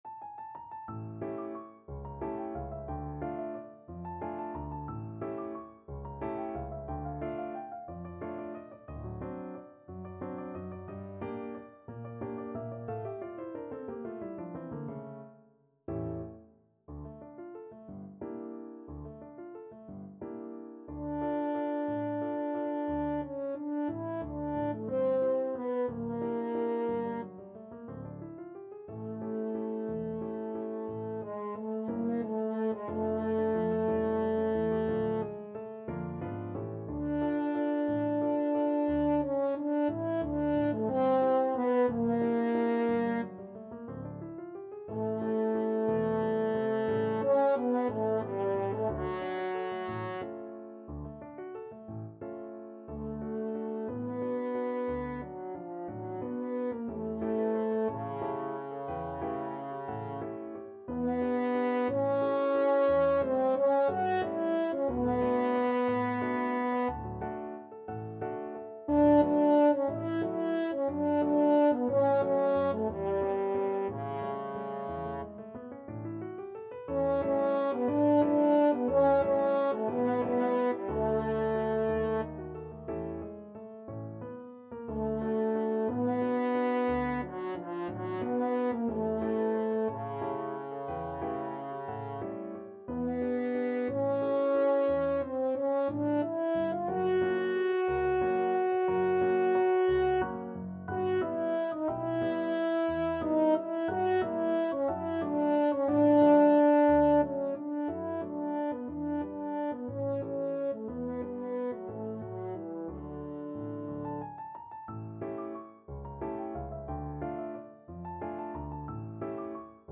French HornFrench Horn
3/4 (View more 3/4 Music)
Allegro movido =180 (View more music marked Allegro)